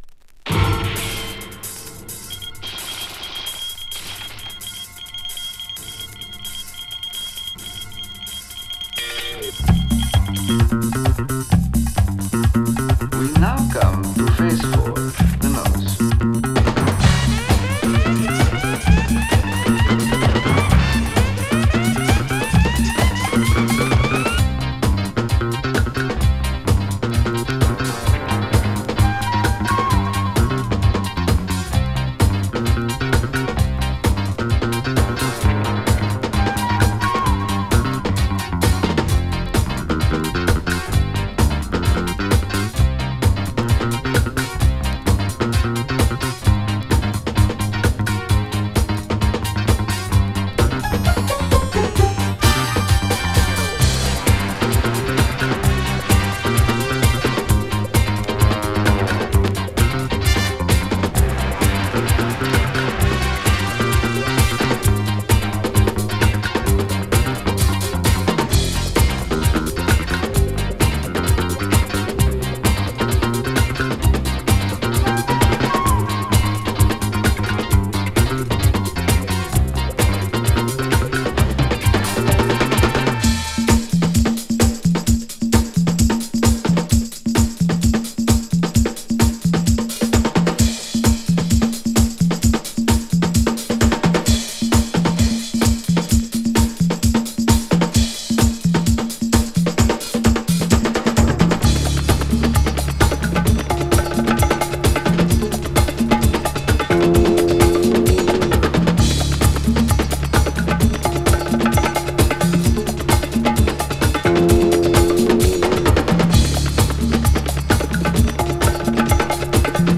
銃声から始まるスパイ映画のサントラのようなスリリングな生音ブレイクビーツハウス